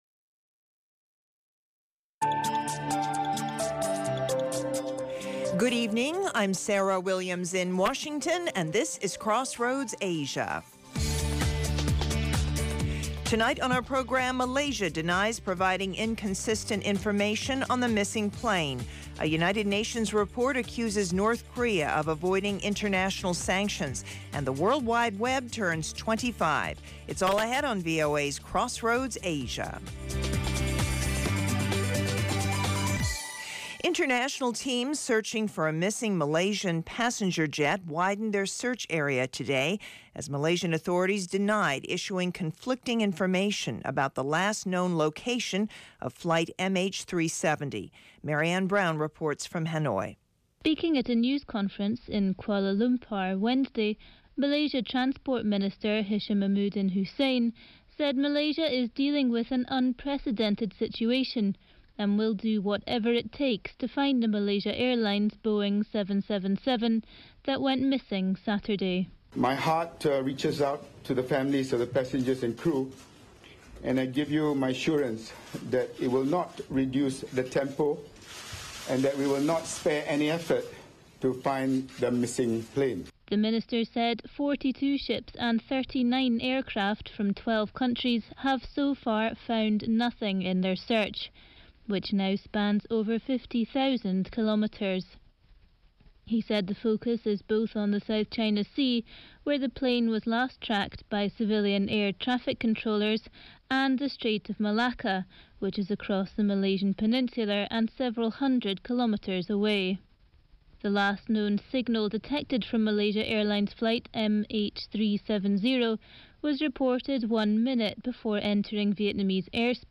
Crossroads Asia offers unique stories and perspectives -- with in-depth interviews, and analysis.